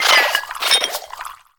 Cri de Théffroyable dans Pokémon HOME.